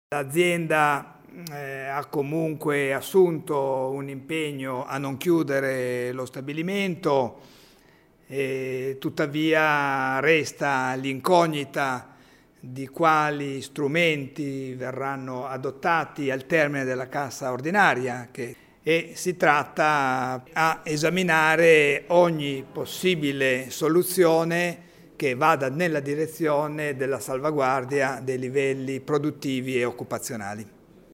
Ascolta l’assessore provinciale al lavoro Paolo Rebaudengo